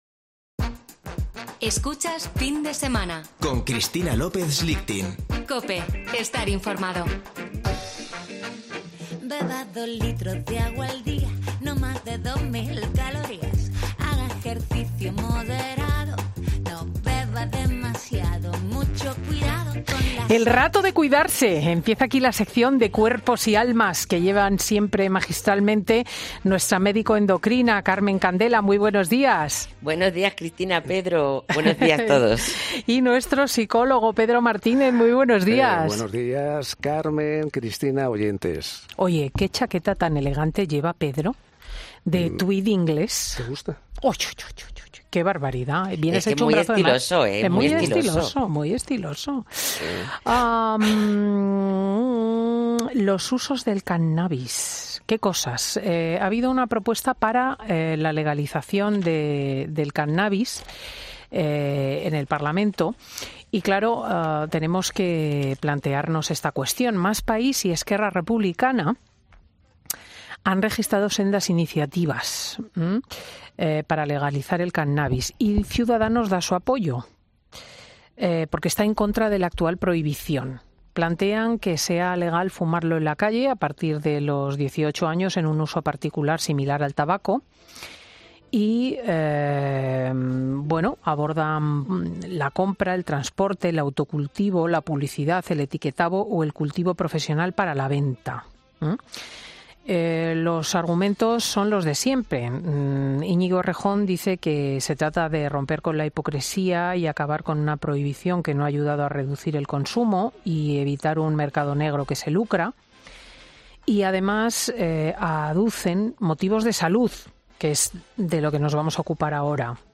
AUDIO: En Fin de Semana hablamos con nuestros doctores sobre los usos del cannabis y si sería buena idea regularizar esta droga en nuestro país